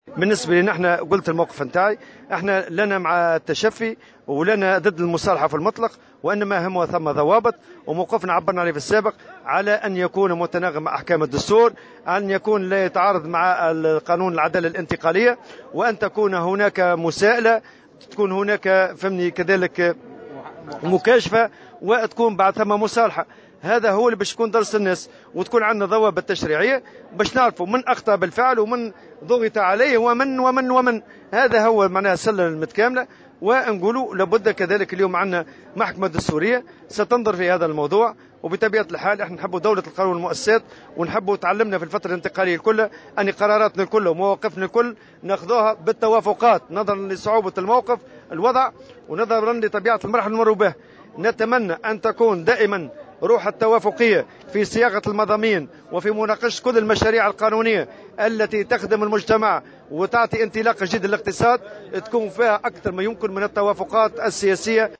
وأكّد الطبوبي في تصريح لمراسلة الجوهرة اف ام لدى إشرافه على يوم العلم بمقر الاتحاد الجهوي للشغل بجندوبة، ضرورة أن تتم المصالحة في إطار ضوابط متناغمة مع الدستور ولا تتعارض مع قانون العدالة الانتقالية.